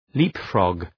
Shkrimi fonetik {‘li:pfrɒg}